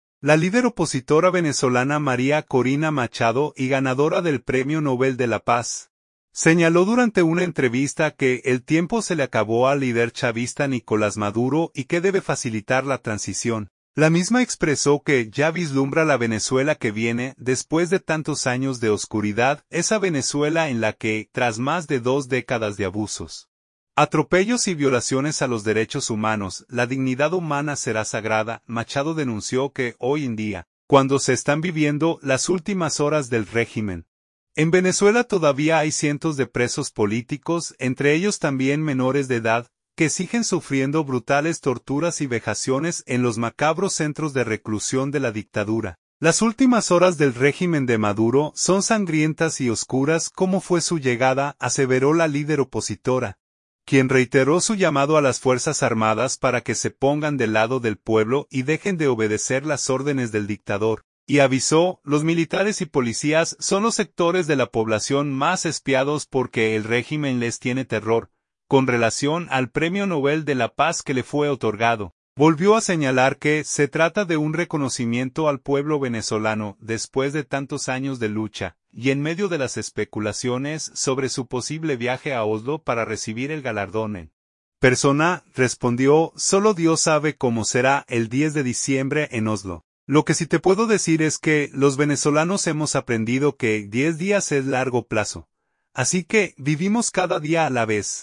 La líder opositora venezolana María Corina Machado, y ganadora del Premio Nobel de la Paz, señaló durante una entrevista que el tiempo se le acabó al líder chavista Nicolás Maduro y que debe facilitar la transición”.